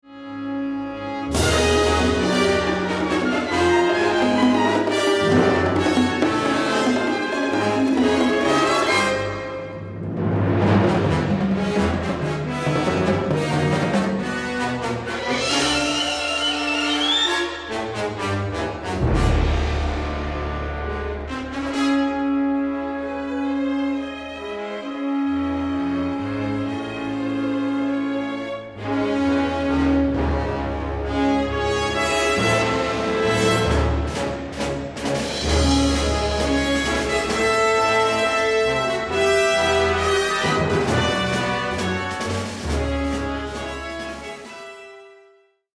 robust, full-blooded music